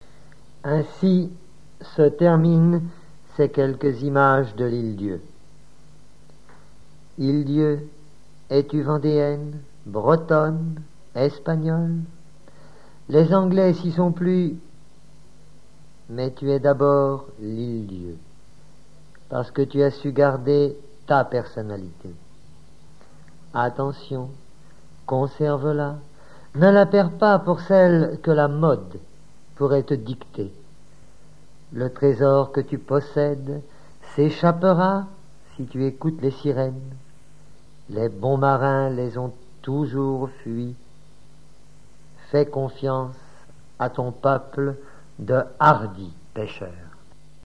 Reportage Folklore vivant - L'Île d'Yeu
reportage et montage sonore relatif à Saint-Sauveur
Catégorie Témoignage